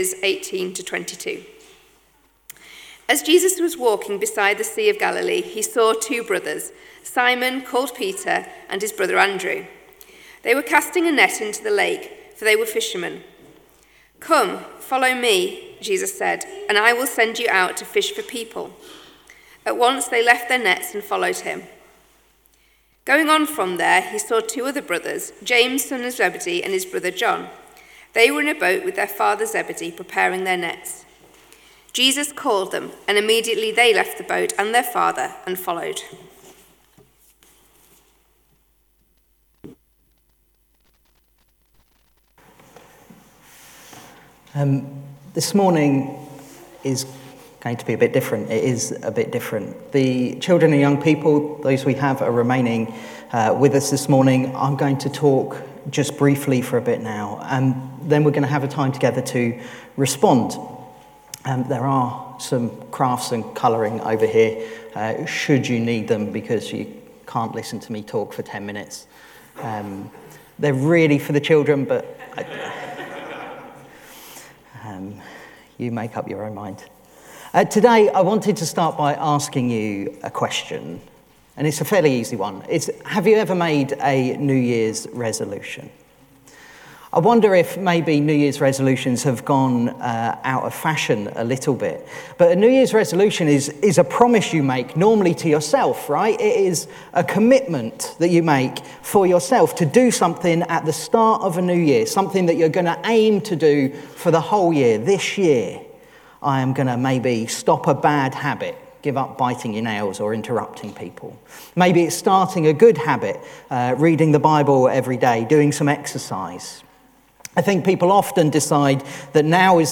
22 Tagged with Morning Service Audio